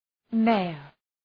Προφορά
{‘meıər}